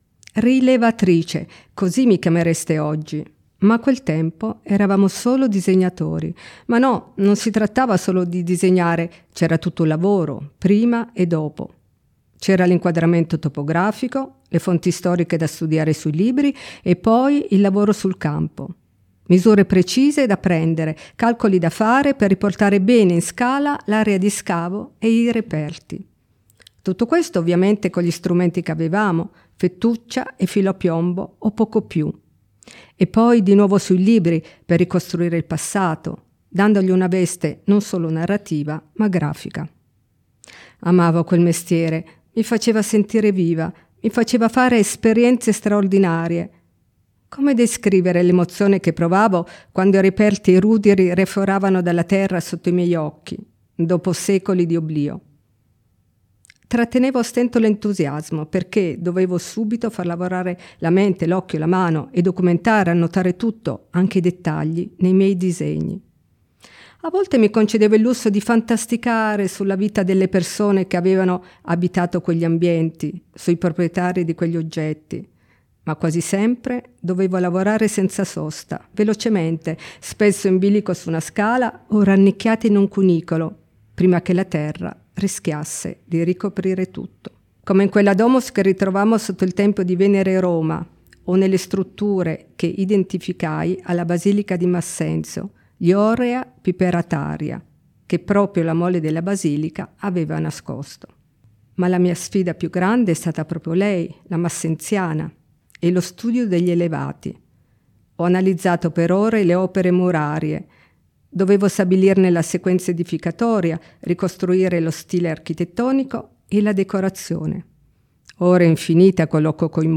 • approfondimenti audio, con il racconto in prima persona di Maria Barosso e le audiodescrizioni delle opere selezionate
Storytelling Maria Barosso: